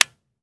Home gmod sound weapons papa320
weap_papa320_disconnector_plr_01.ogg